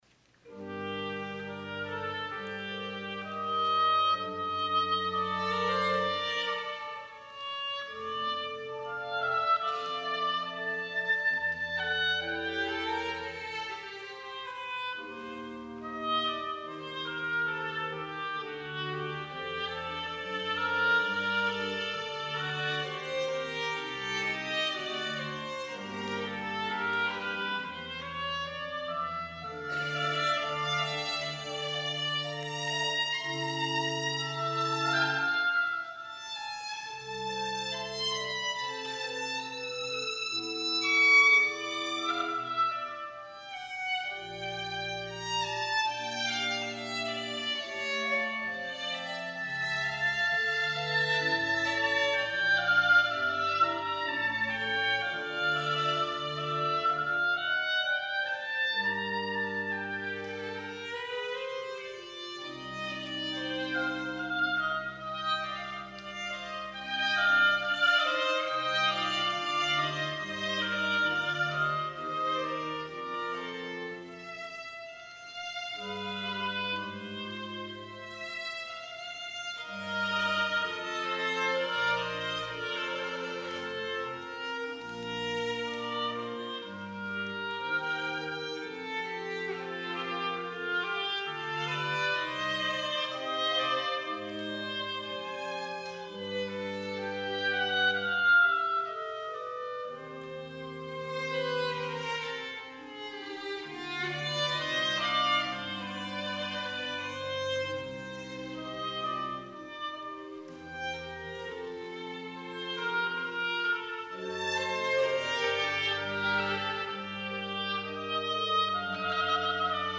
VOLUNTARY Mesto
violin
oboe
organ